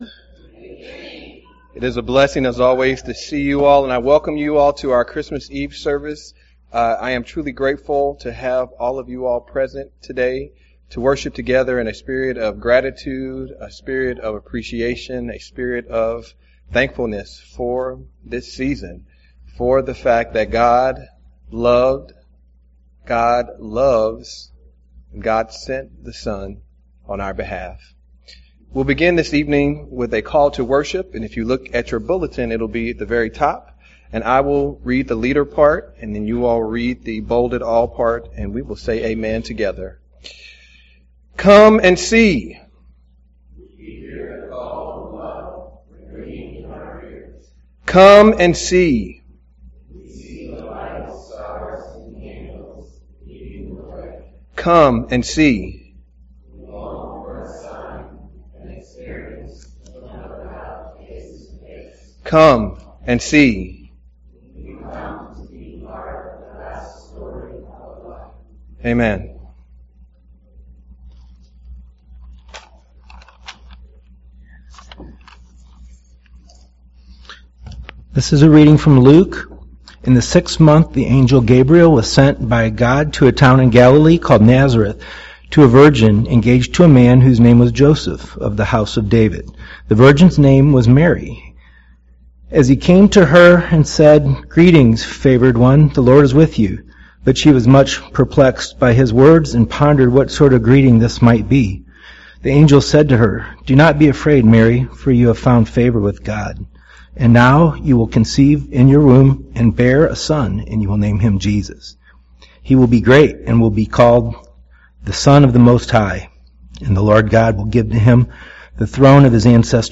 Christmas Eve Service 2017